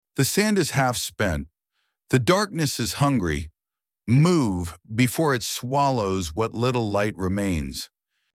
Add voice overs
Urgency push.mp3